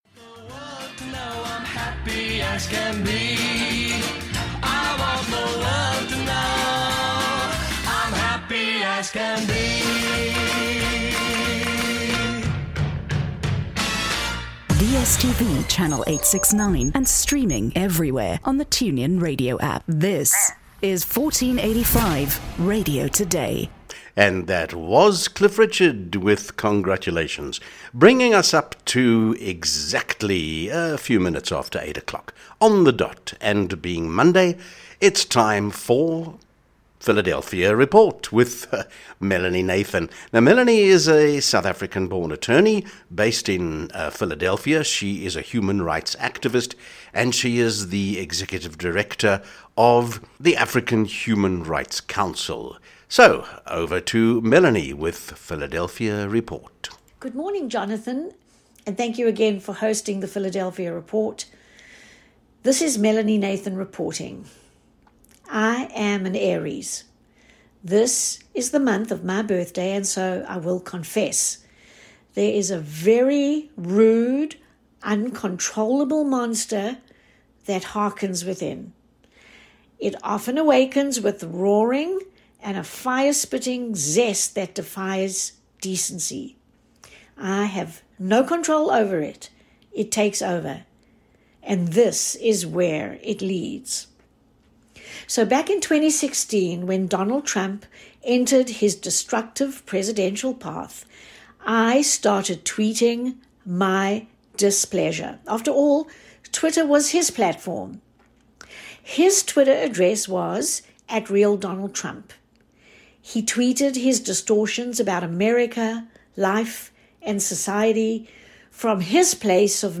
THE PHILADELPHIA REPORT – I was invited to do a weekly series on South African Radio: Each day